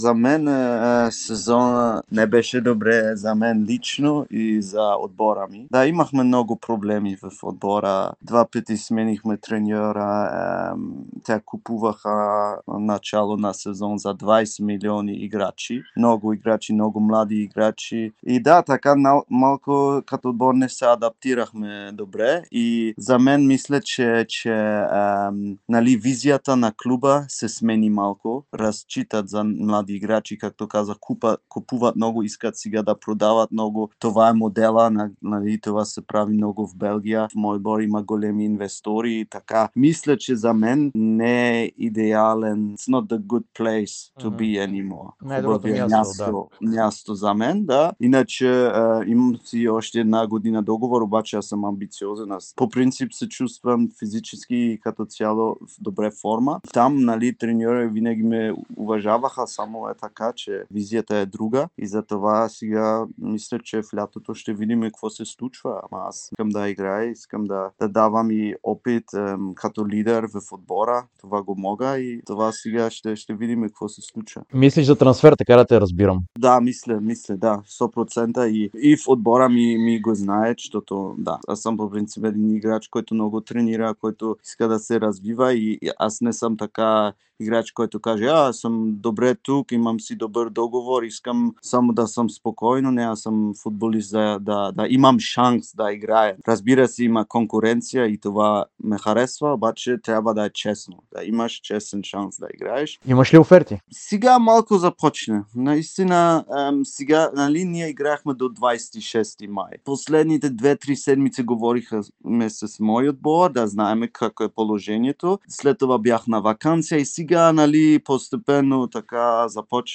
Йорданов даде специално интервю за Дарик радио и dsport, в което говори за впечатленията си от Стипич. Той също така заяви, че обмисля вероятността да напусне белгийския Вестерло и не изключи възможността да заиграе в българското първенство.